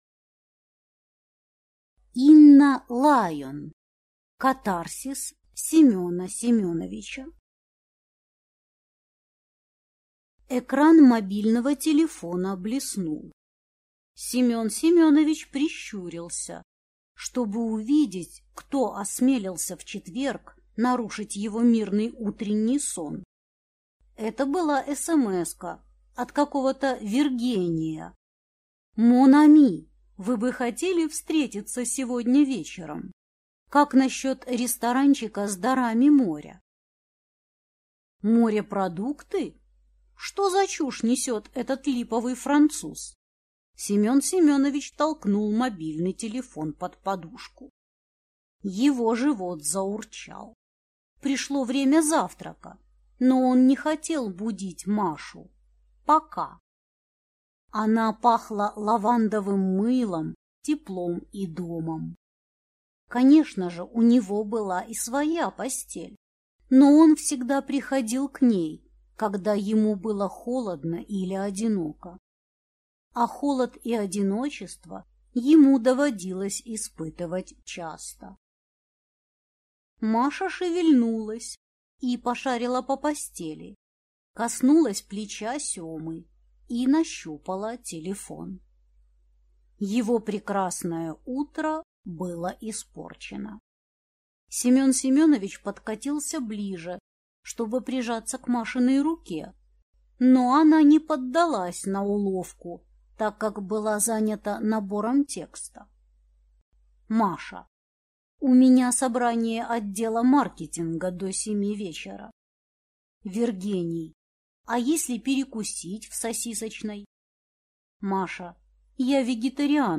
Аудиокнига Катарсис Семёна Семёновича | Библиотека аудиокниг